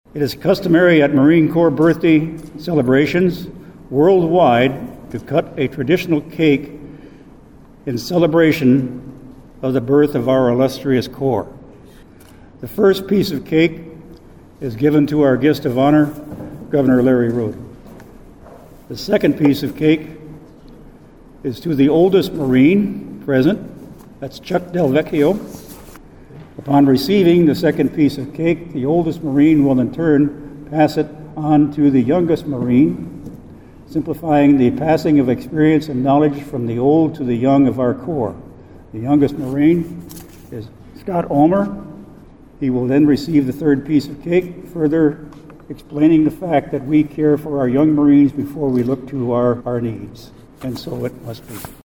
PIERRE, S.D.(DRGNews)-The United States Marine Corps turned 250 years old Monday and South Dakota marked the occasion with a ceremony and official cake cutting in Pierre.